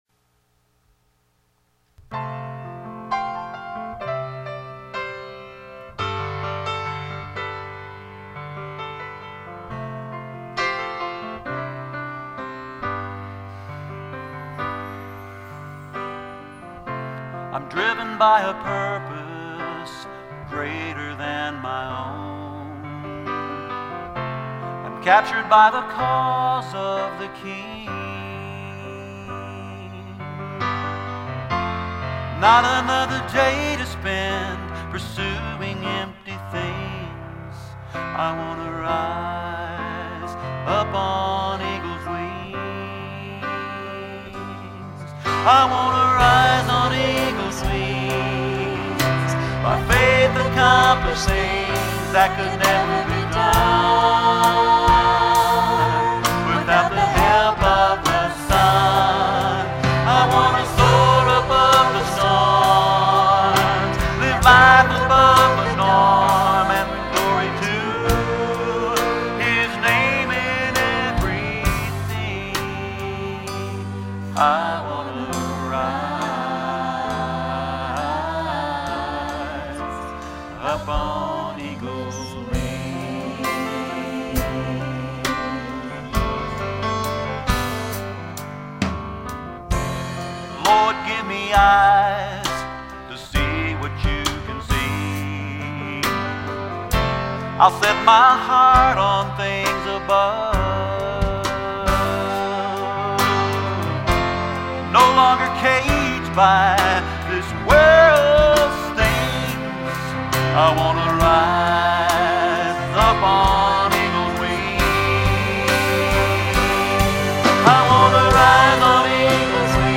1. The sound mix will not always be well balanced. The recordings were taken off the "house mix", which means that adjustments made for "stage volume" and "room EQ" have an impact on the recording. Unfortunately, that often means the bass gets turned down as a result of someone having the bass too high in their monitor, or any other random boominess that shows up.
2. You often get imprecise starts and stops to the songs as that is dependent upon the sound man hitting the button to tell the recorder to start a new track. We often go from one song right into another, so this can be a difficult task.